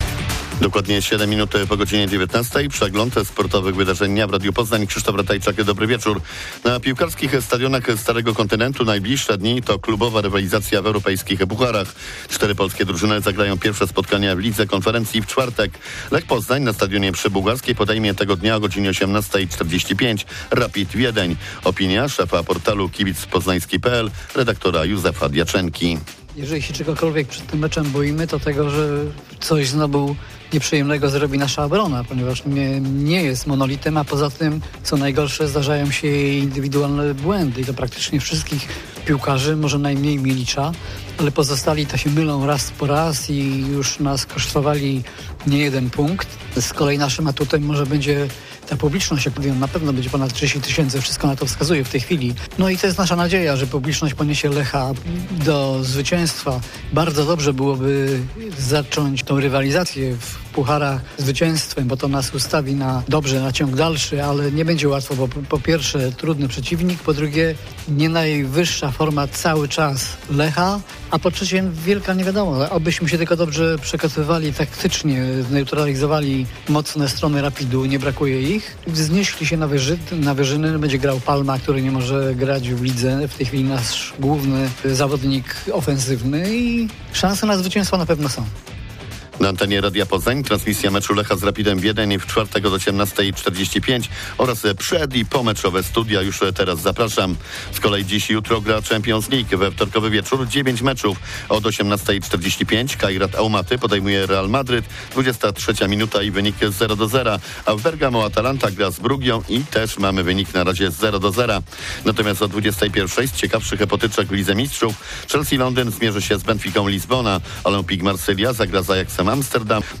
30.09.2025 SERWIS SPORTOWY GODZ. 19:05